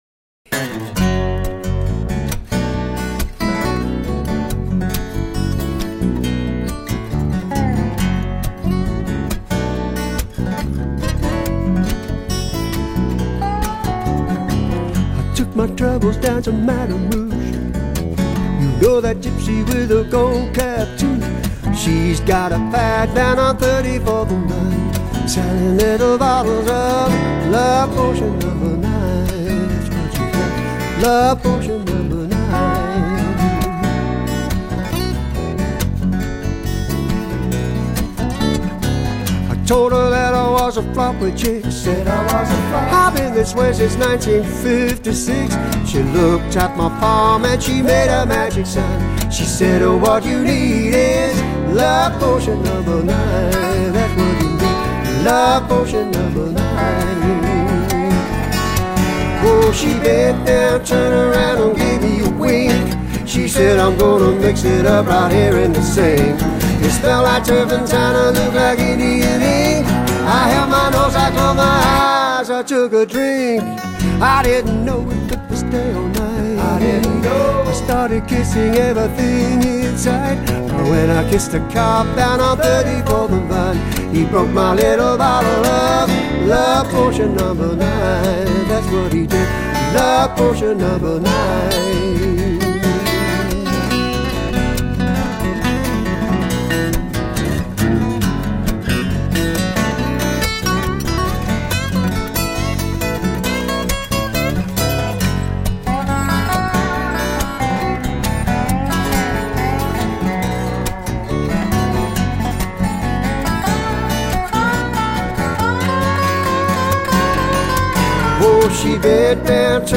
音乐类型:  新世纪